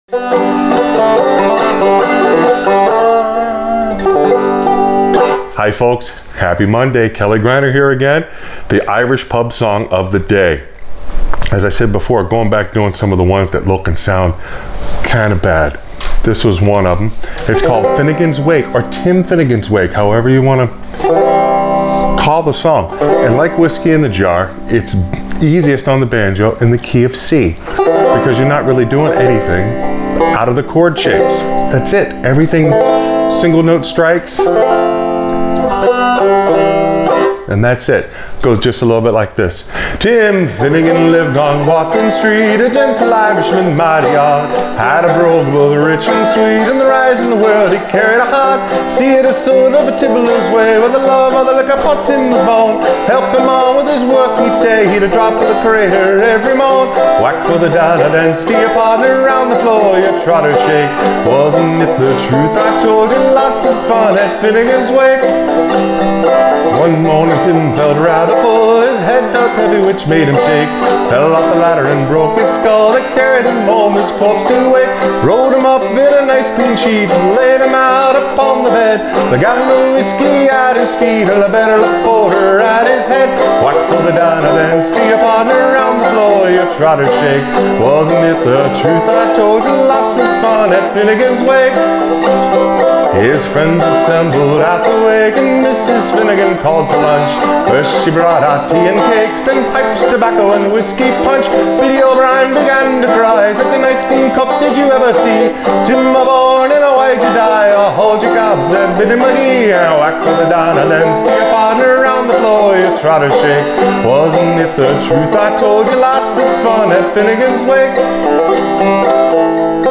Clawhammer BanjoFrailing BanjoInstructionIrish Pub Song Of The Day
It’s a great song about Life, Love and Resurrection! In other words it’s a great street ballad that’s now sung around the world!